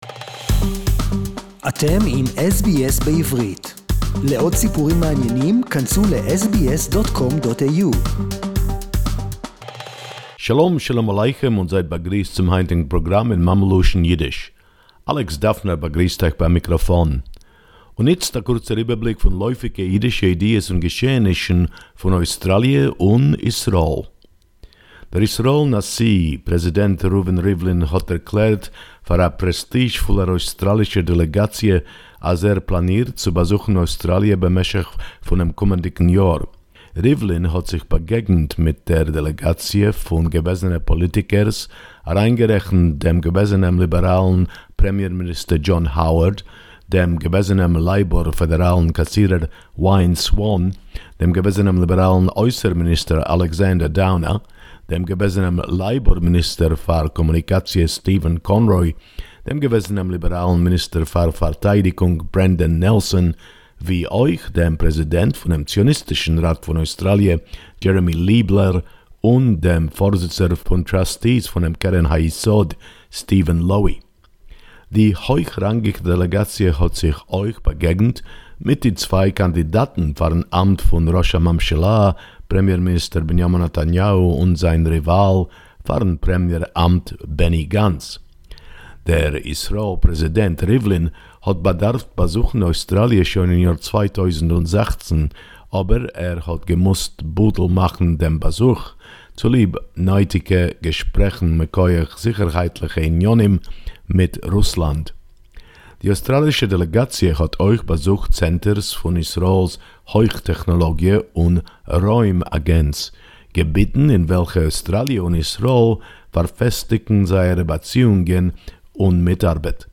Yiddish report